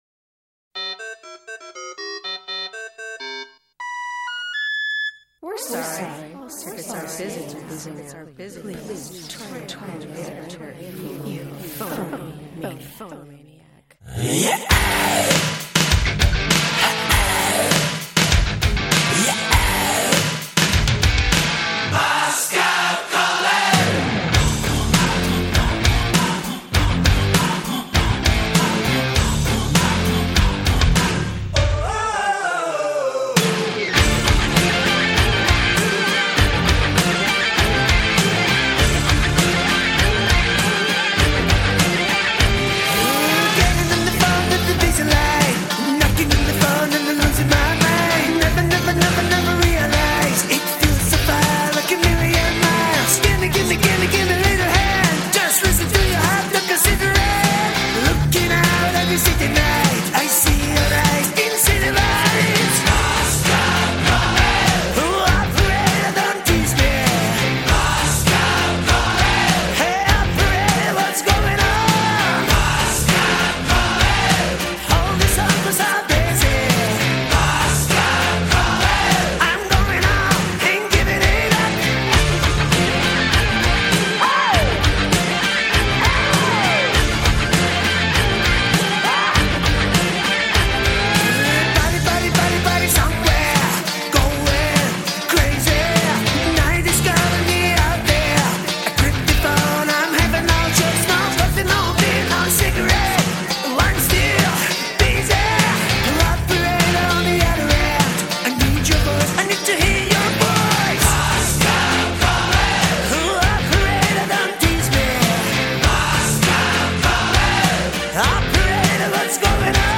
Жанр: hardrock